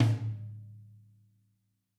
tom4.ogg